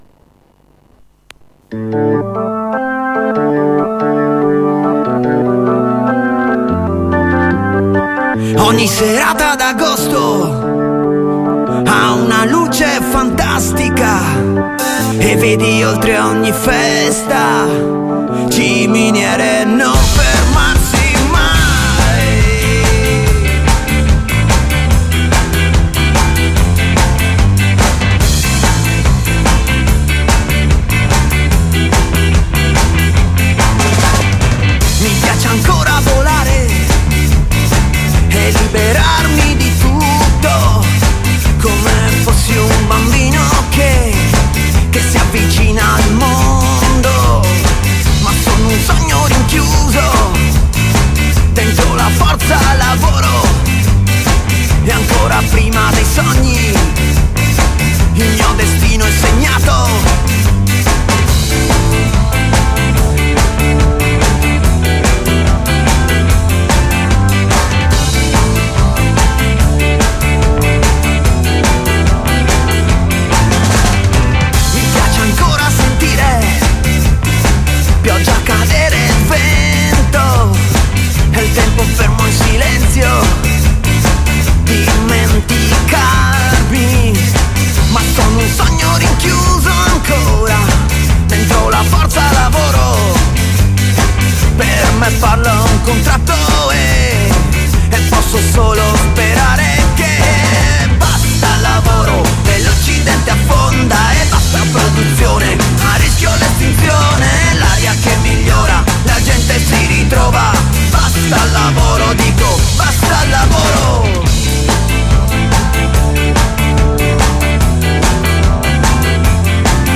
Presidio al ministero dell'istruzione a Roma